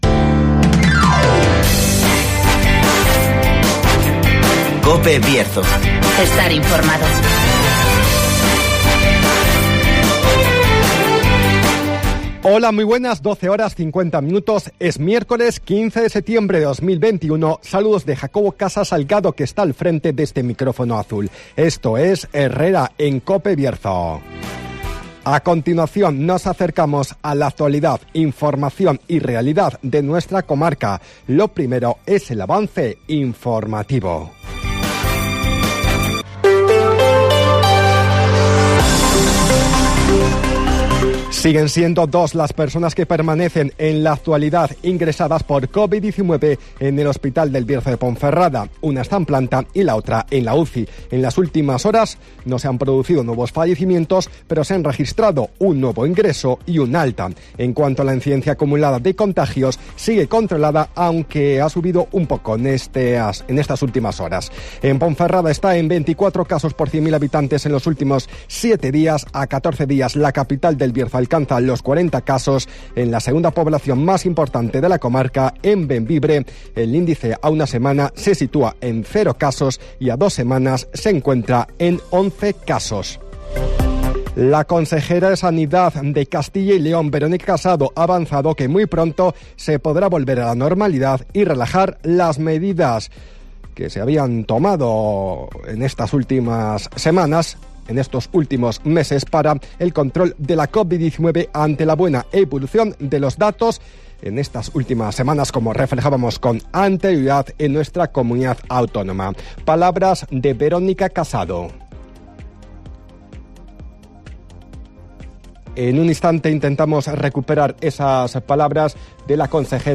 Avance informativo, El Tiempo y Agenda